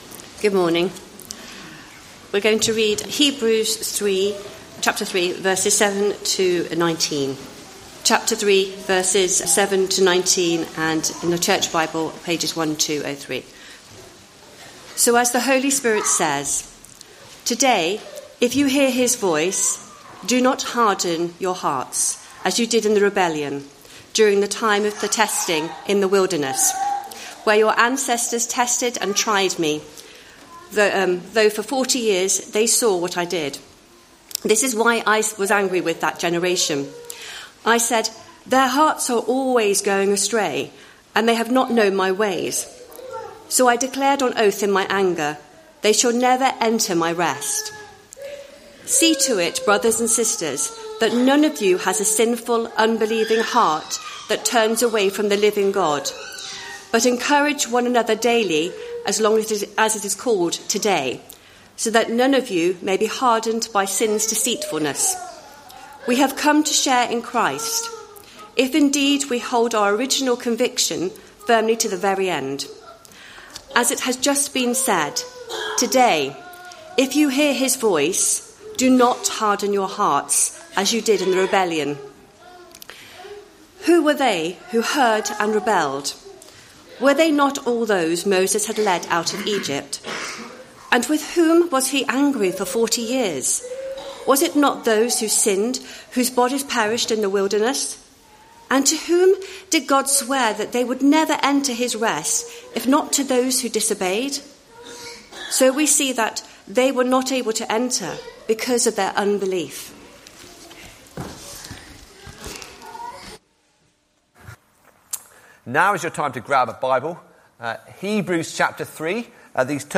Hebrews 3:7-19; 17 November 2024, Morning Service.